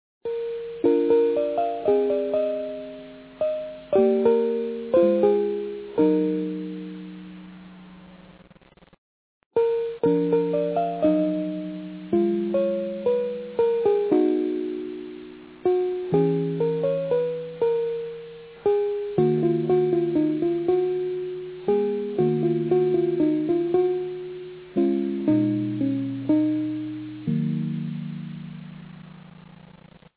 long wave radio for nostalgic memories.